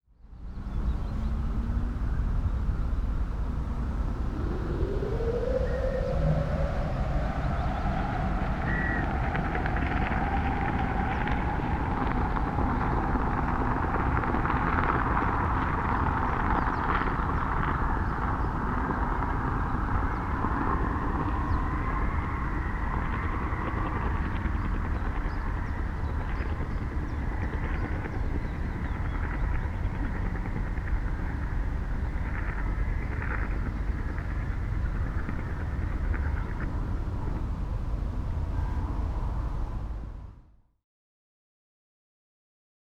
Glider Haulage Cable Sound
transport
Glider Haulage Cable